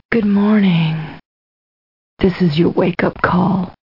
wakecall.mp3